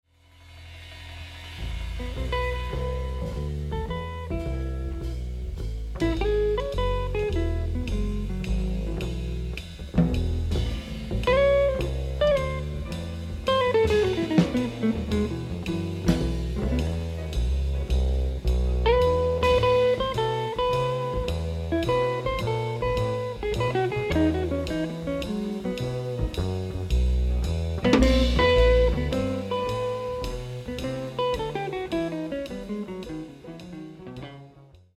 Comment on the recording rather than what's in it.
Recorded live at Yellowfingers,